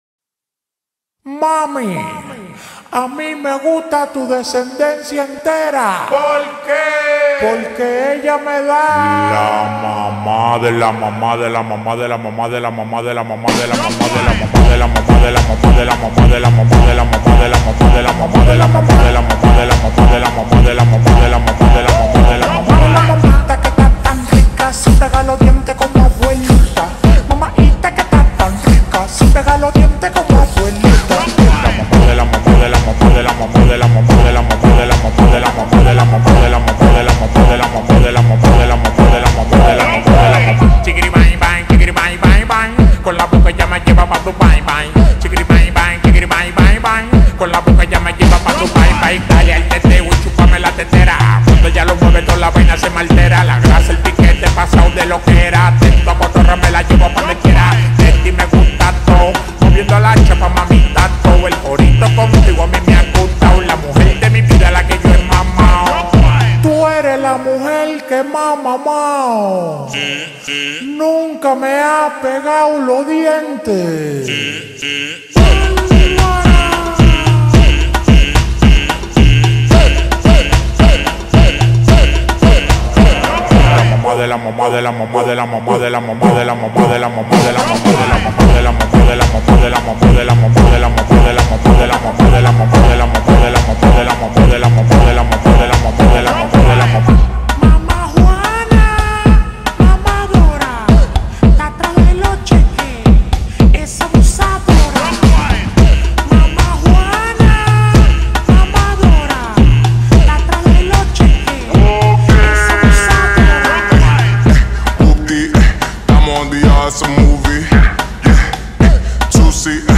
тик ток ремикс